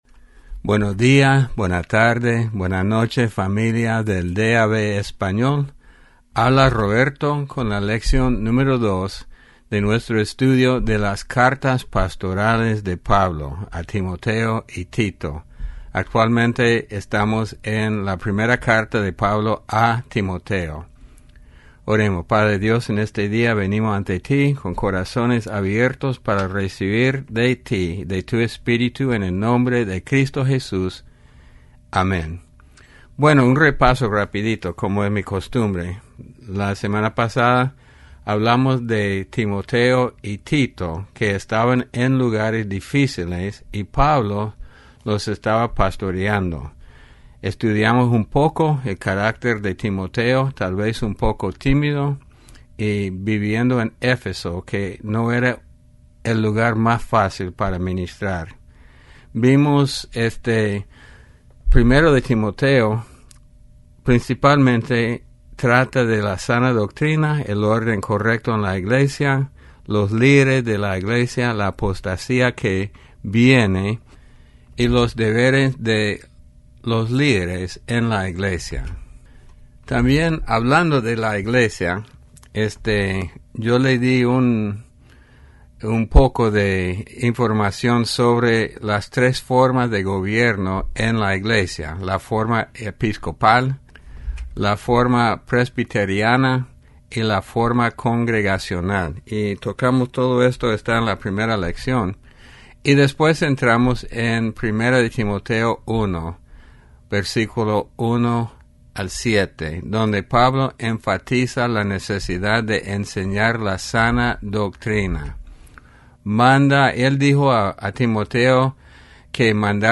Lección 02 Las Cartas Pastorales (Timoteo y Tito)